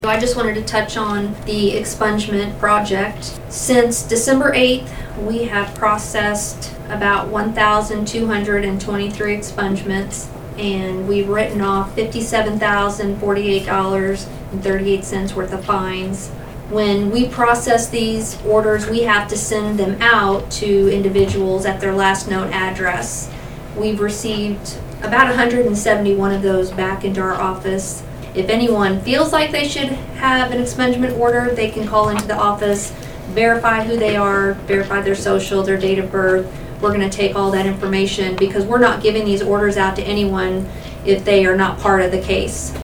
Saline County Circuit Clerk Becky Uhlich gave an update on marijuana expungements during the Saline County Commission meeting on Wednesday, May 24, 2023.